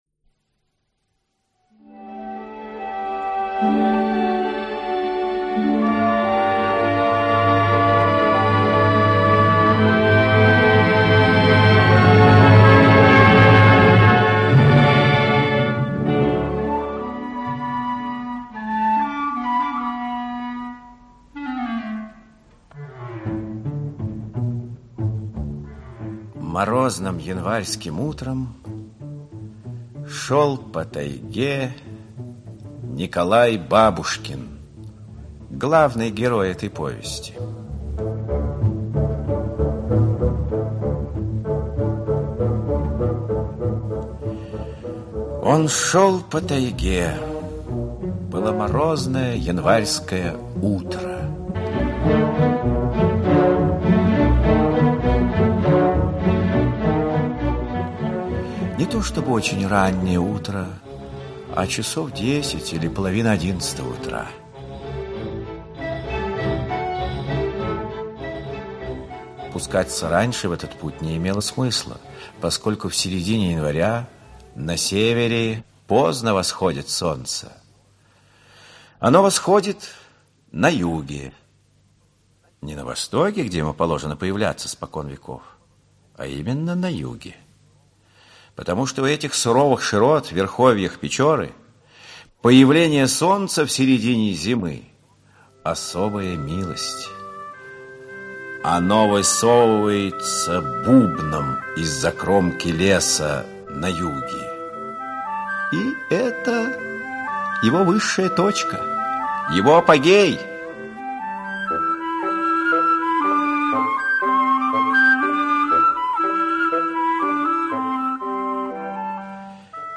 Аудиокнига Молодо-зелено | Библиотека аудиокниг
Aудиокнига Молодо-зелено Автор Александр Евсеевич Рекемчук Читает аудиокнигу Актерский коллектив.